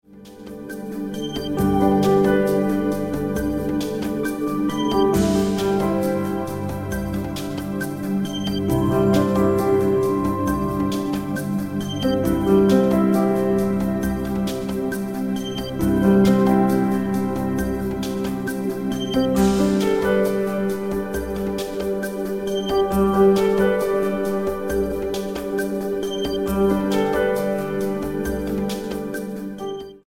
134 BPM
Light rhythmic texture with melodic piano stack lead.